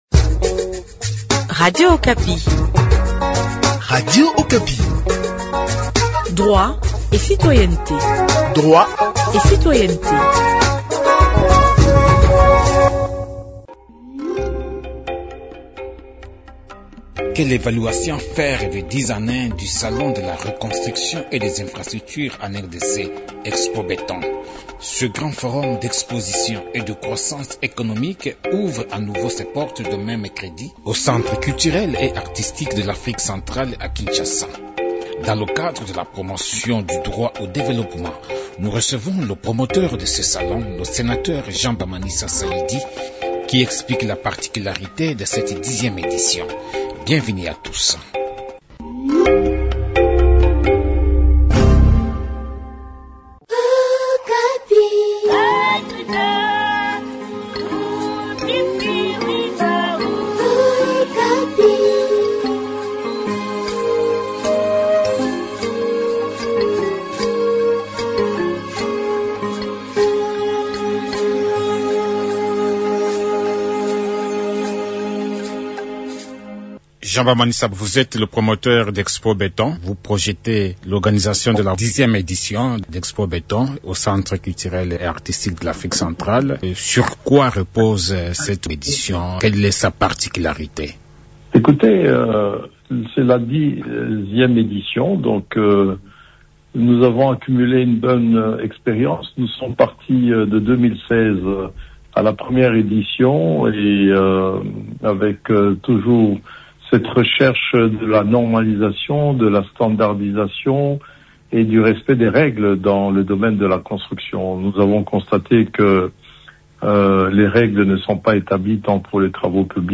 Mais depuis 10 ans d’alerte, que retenir de concret de cette plateforme ? Dans le cadre de la promotion du droit au développement, nous recevons le promoteur de ce salon, le sénateur Jean-Bamanisa Saidi. Il explique la particularité de cette 10eme édition.